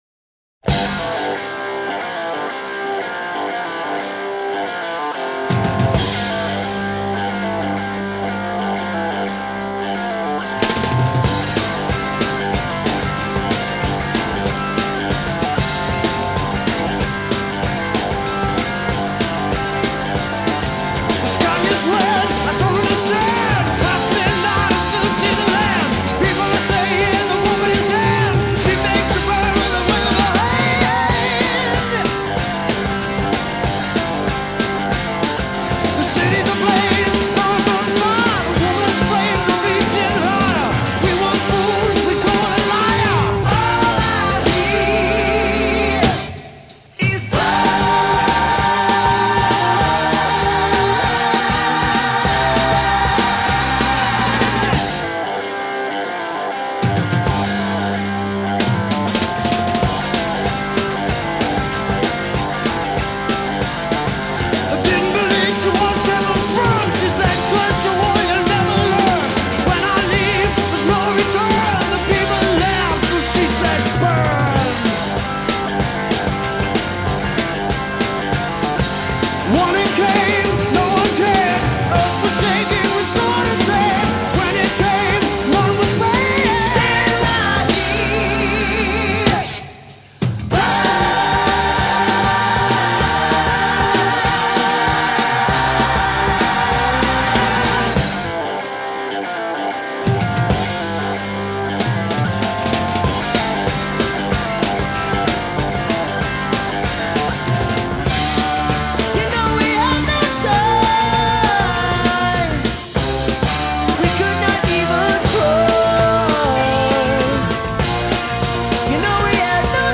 Hard Rock, Progressive Rock